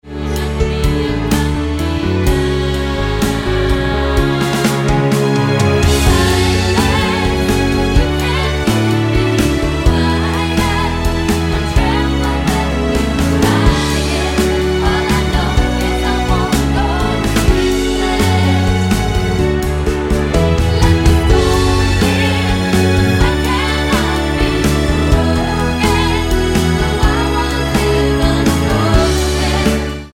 Tonart:F#m mit Chor